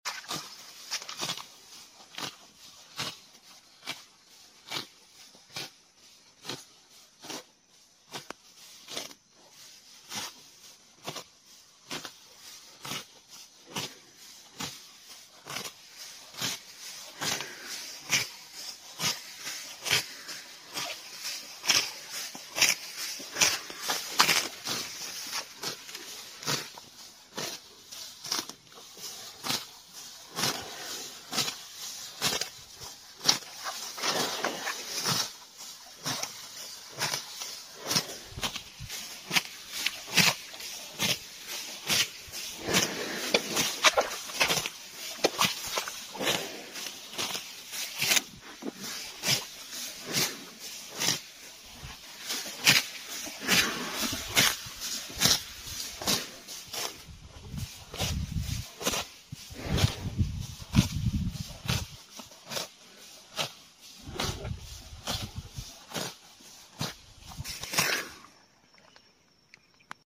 Cow Grazing ASMR | Natural sound effects free download
Cow Grazing ASMR | Natural Valley Serenity